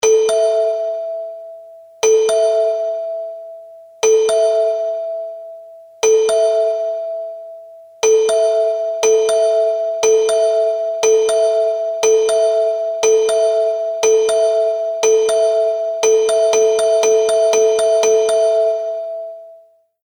| bell repeat effect |